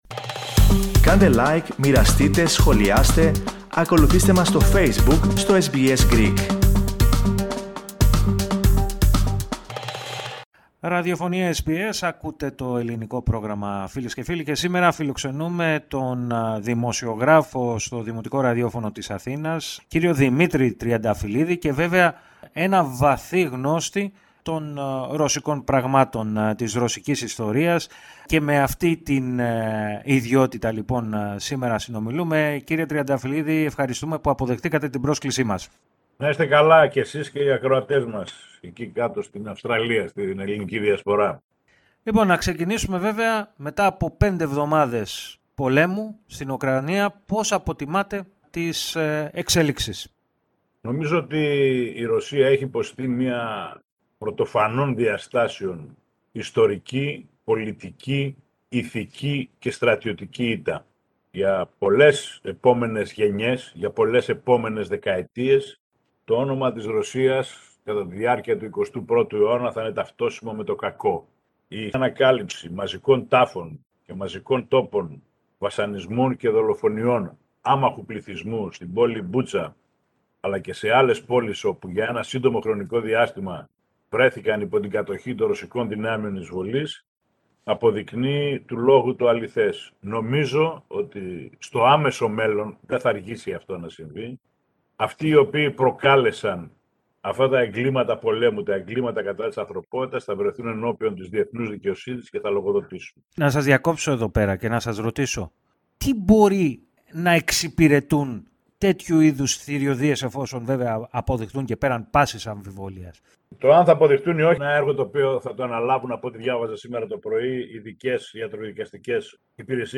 μίλησε στο Ελληνικό Πρόγραμμα της ραδιοφωνίας SBS, κάνοντας τη δική του αποτίμηση για τον πόλεμο στην Ουκρανία.